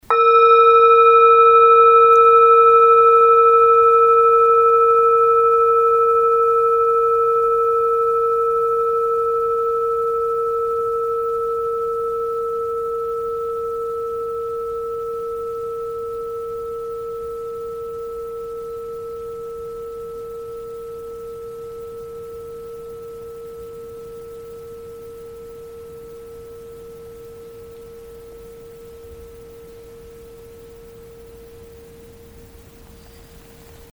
Struck or rubbed, the bowls produce a long-lasting, overtone-rich, and fine sound.
Sound sample Arhat singing bowl 300g:
Arhat-Klangschale-300g-Hoerprobe.mp3